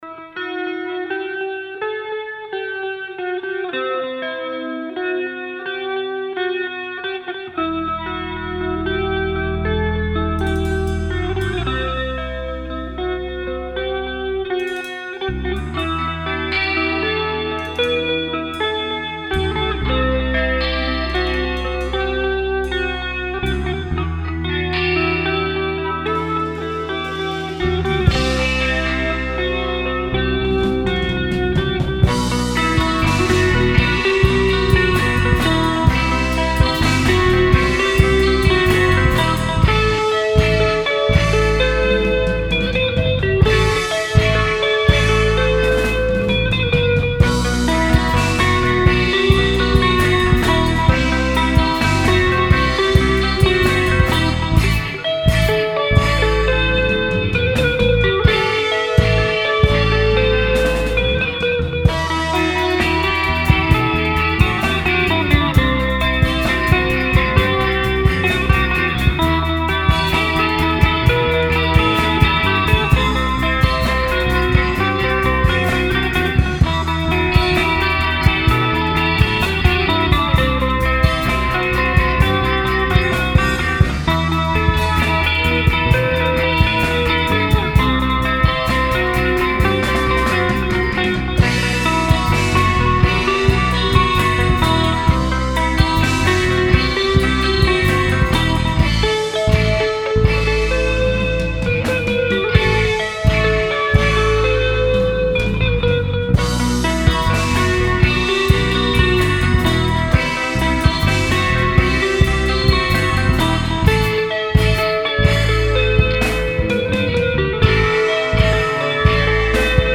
Recorded on 4-Track at the Moor Lane Farm rehearsal room